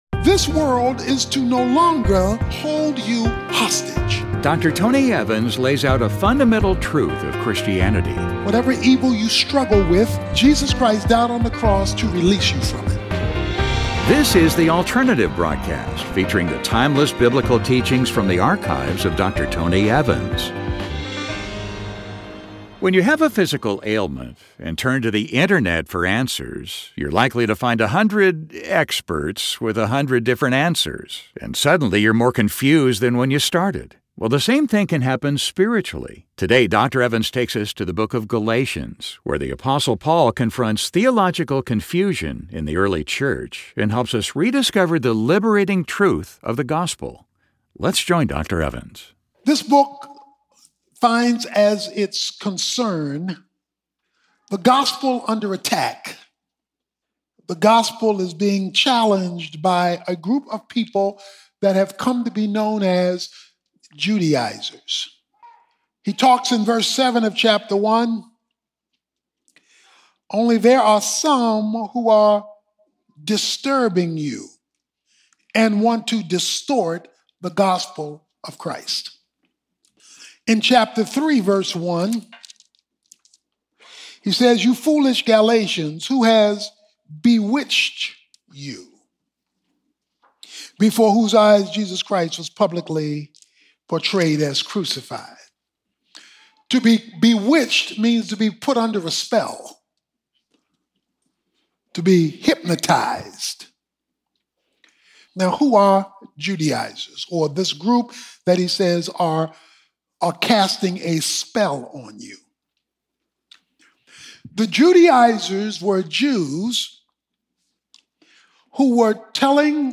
In this message, Dr. Tony Evans clears up spiritual confusion as he takes a look at the liberating truth of the Gospel.